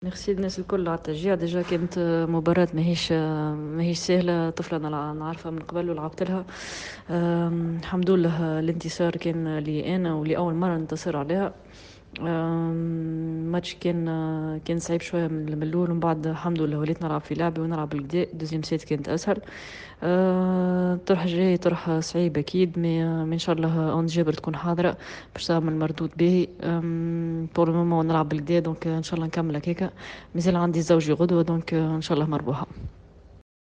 أنس جابر في تصريح خاص بجوهرة أفام بعد مباراة اليوم